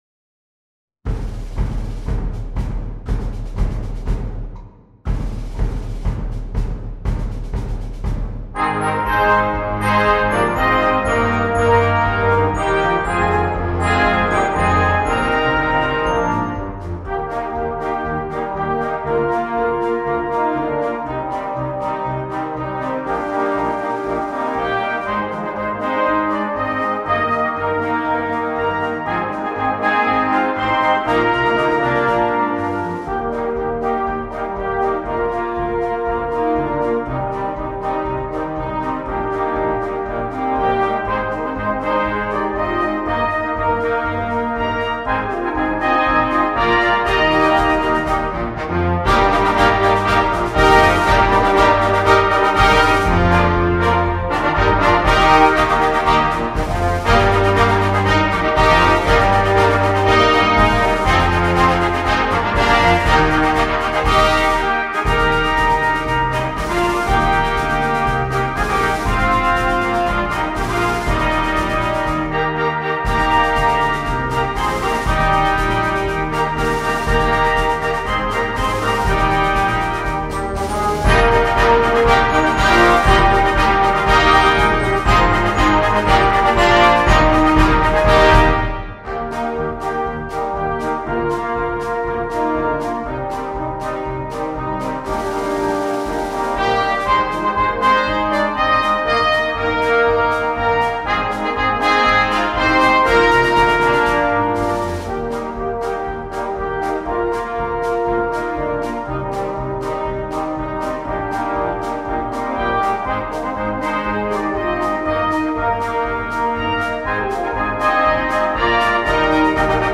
Full Band
without solo instrument
March